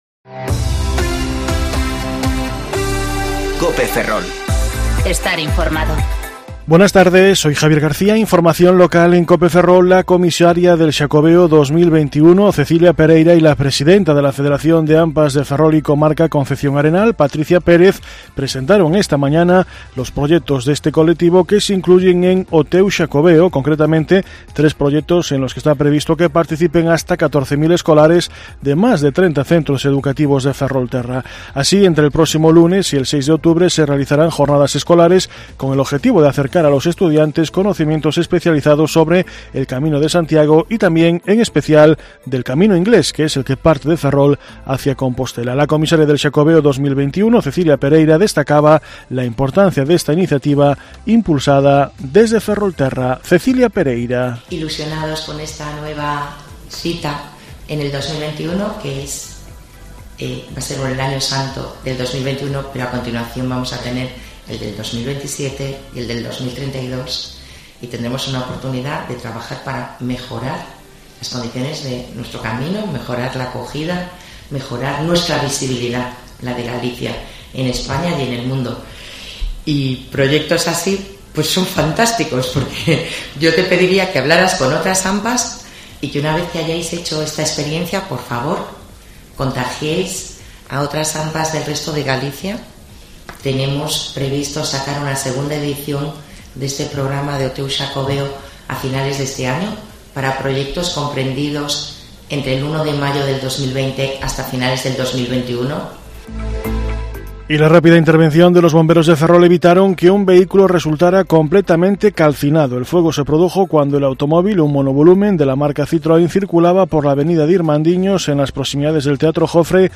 Informativo Mediodía Cope Ferrol 25/09/2019 (De14.20 a 14.30 horas)